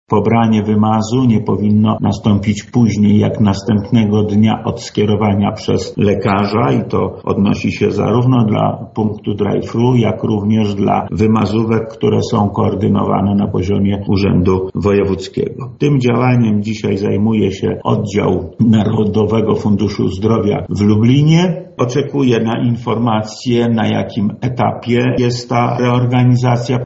Lech Sprawka, Wojewoda Lubelski
Wprowadzamy reorganizację by poprawić sytuację naszych pacjentów – mówi Lech Sprawka, Wojewoda Lubelski.